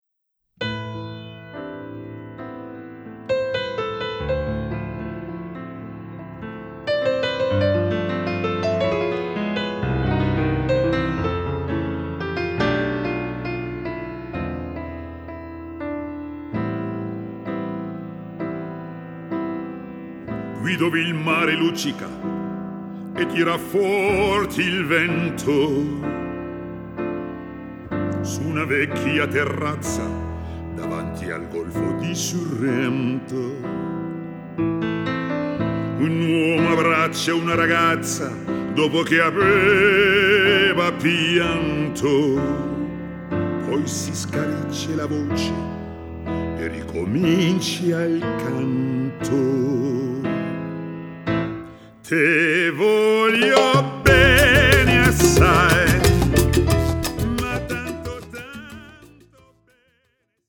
Salsa-Klänge